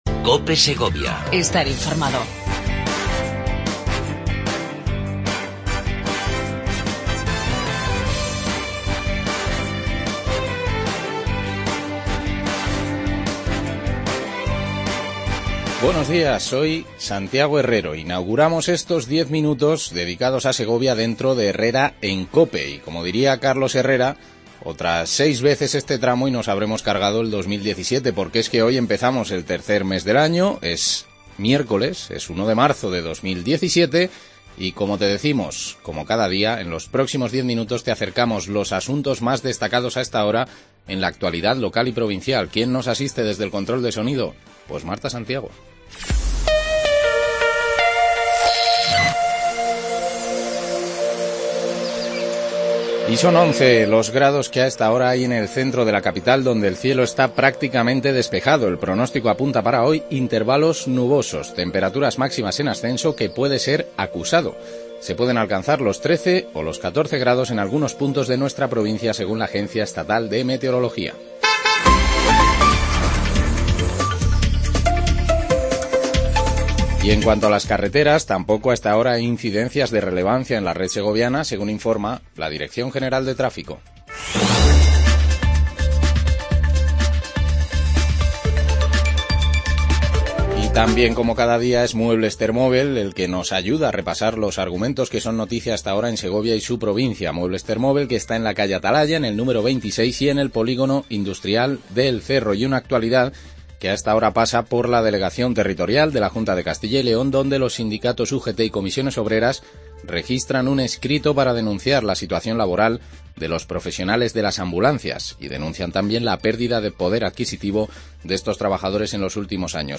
Avance de las noticias más destacadas del día. Entrevista